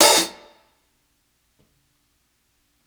60s_OPEN HH_1.wav